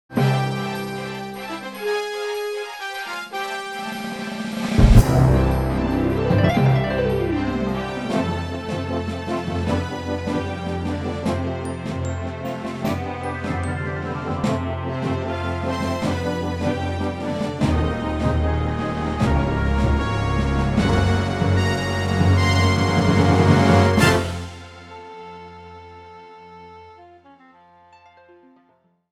Original / Reverb